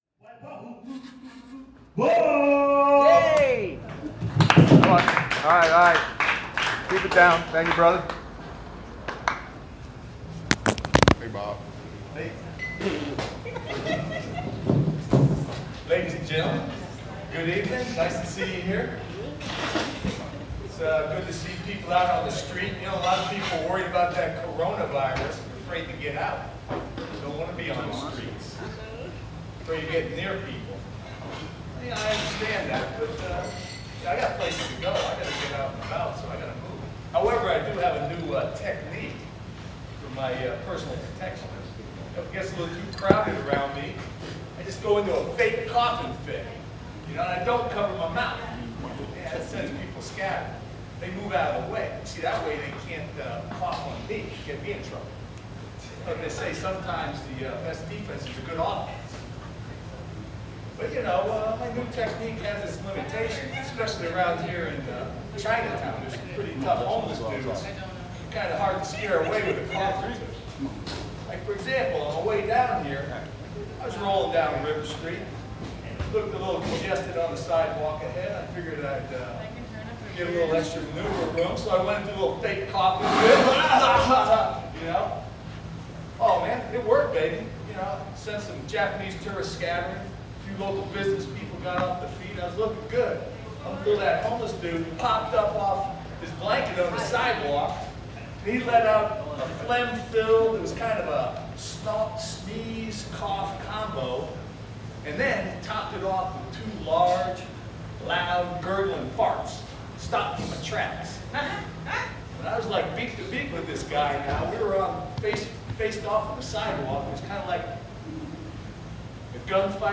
Stand-Up Comedy – Open Mic at the Downbeat Lounge – 3 March 2020